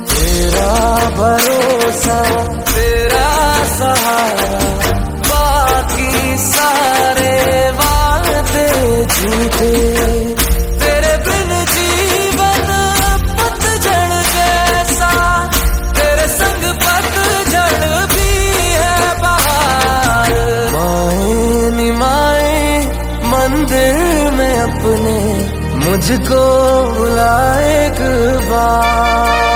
Devotional Ringtones
Instrumental Ringtones